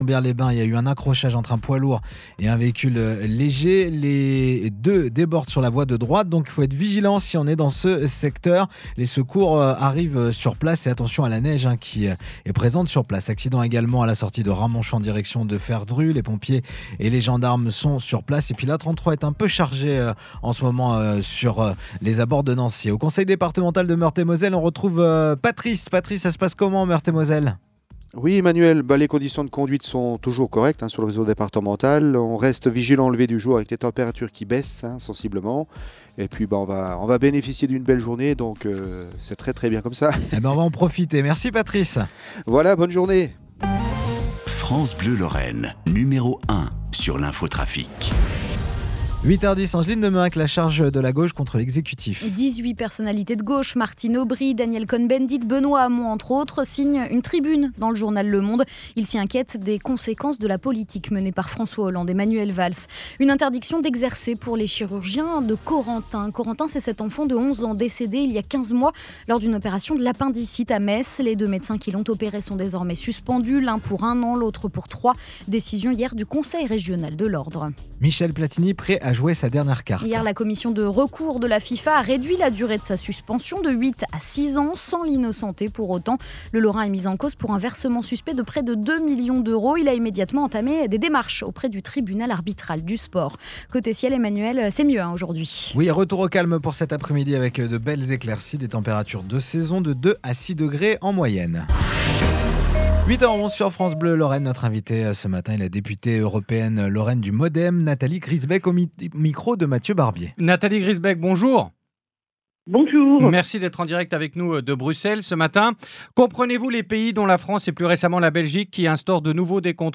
Invitée du journal de France Bleu Lorraine